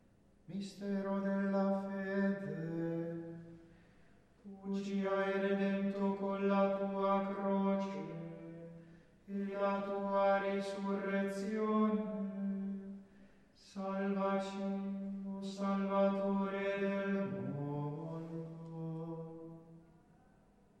Esecuzione a cura della Parrocchia S. Famiglia in Rogoredo